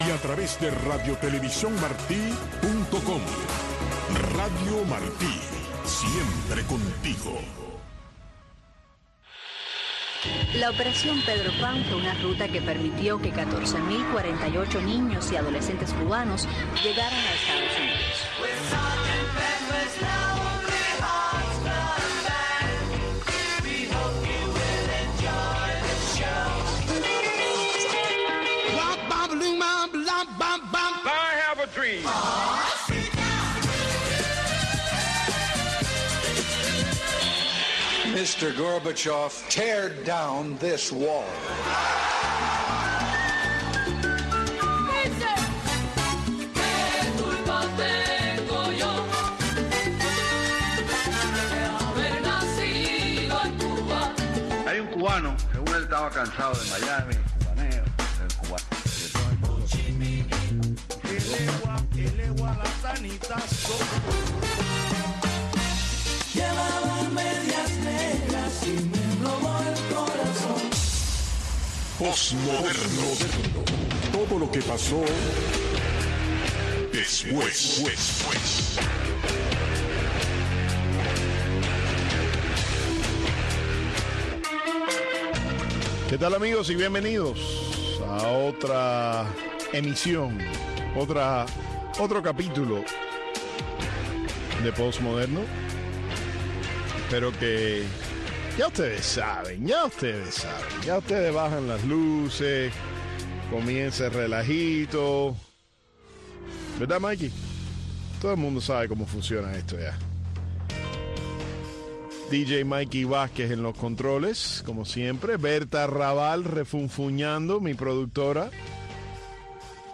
guía al público por una aventura musical que incluye todos los géneros y estilos que ayudaron a dar forma a la moda